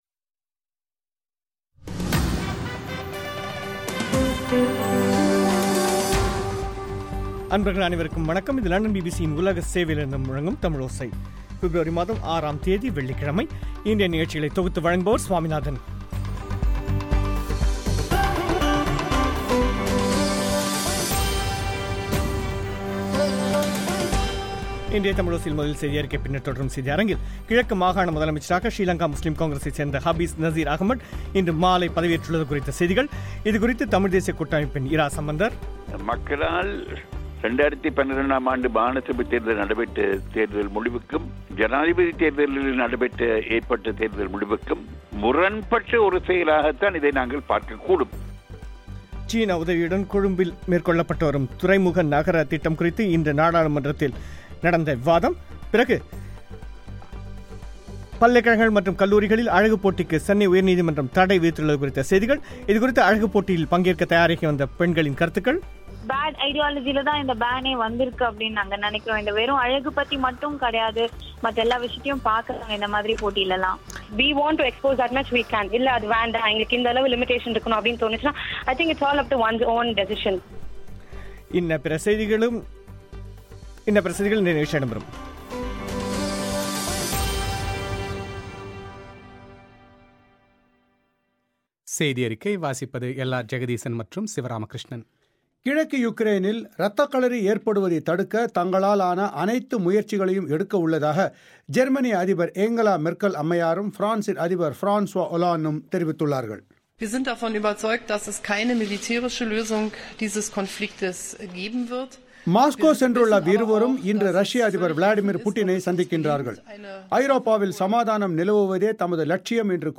பிபிசி தமிழோசை பிப் 6, முக்கியச் செய்திகள்